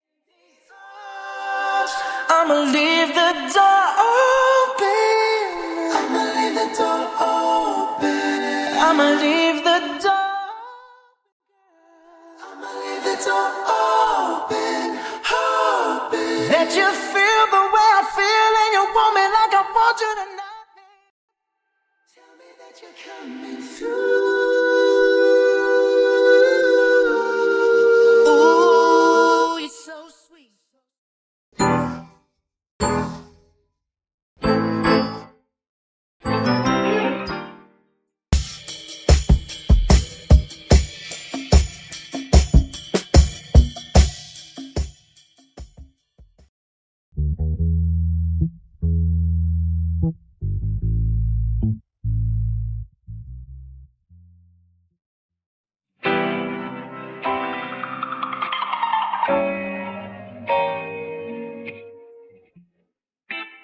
Basslint Guitar Stem
Keys Stem
Percussion & Drums Stem
Strings & Backing Vocals Stem